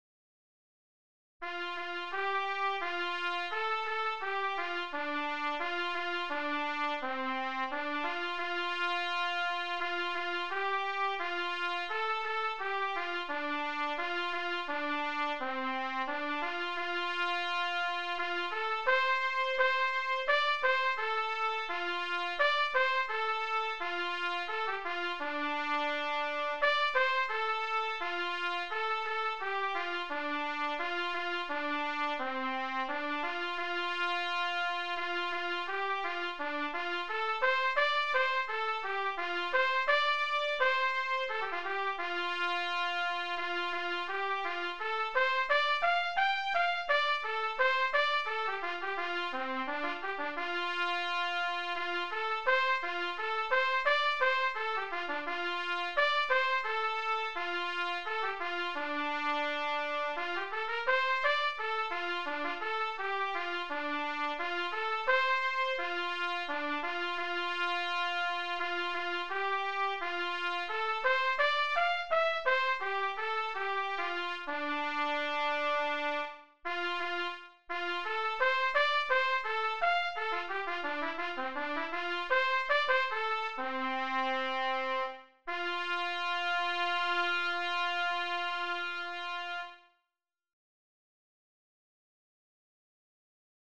DIGITAL SHEET MUSIC - TRUMPET SOLO
Sacred Music, Hymns, Unaccompanied Solo